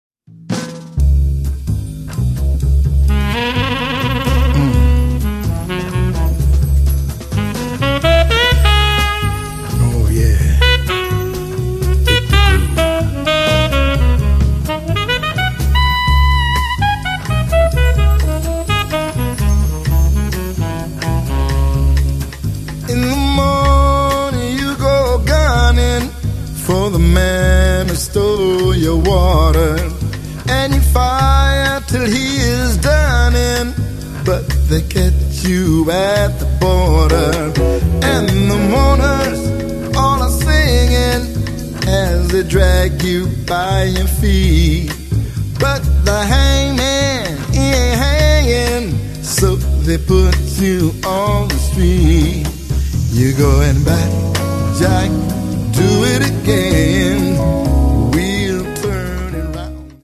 Un disco ballabile, anche ad occhi chiusi.